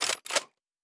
pgs/Assets/Audio/Sci-Fi Sounds/Weapons/Weapon 01 Reload 3.wav at master
Weapon 01 Reload 3.wav